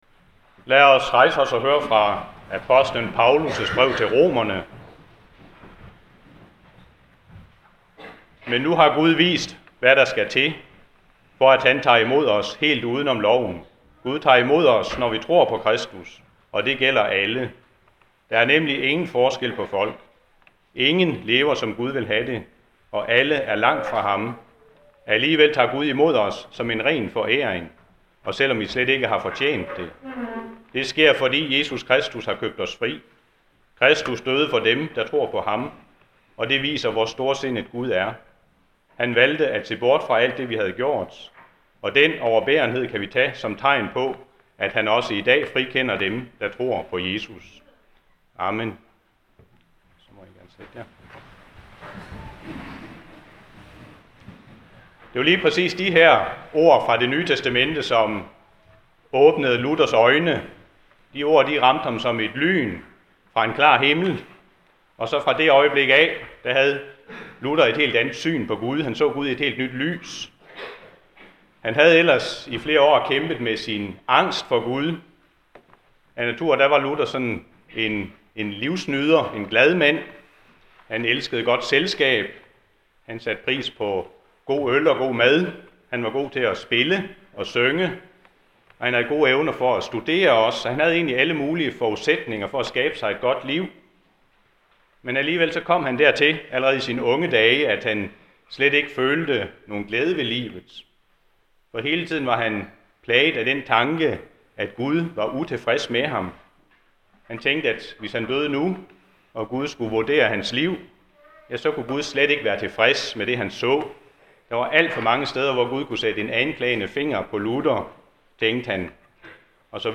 Reformationsgudstjeneste - forslag til prædiken (lyd/mp3)
praediken.mp3